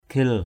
/kʱɪl/ (d.) mộc, khiên = bouclier. shield.